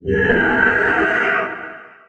48d440e14c Divergent / mods / Soundscape Overhaul / gamedata / sounds / monsters / poltergeist / attack_1.ogg 36 KiB (Stored with Git LFS) Raw History
attack_1.ogg